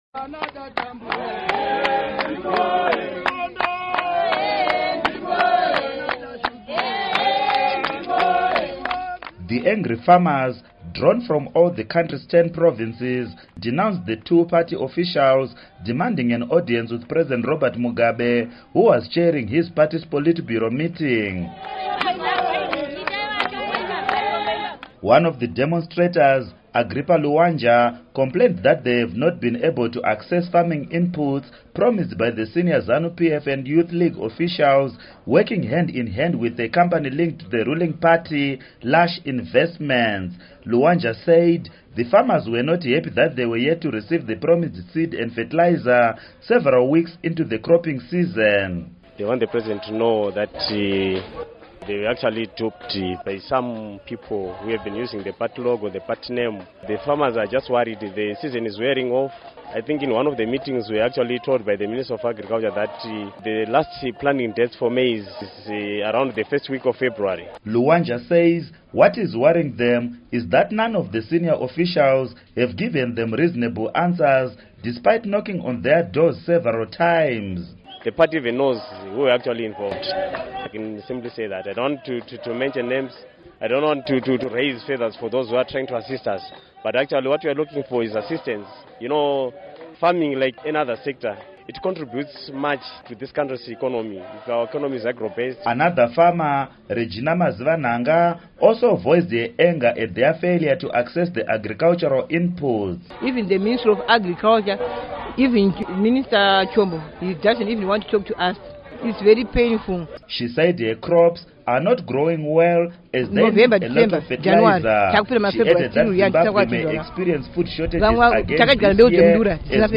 The angry farmers, drawn from all the country’s 10 provinces, sang revolutionary songs and denounced the two party officials, demanding an audience with President Robert Mugabe, who was chairing his party’s Politburo meeting.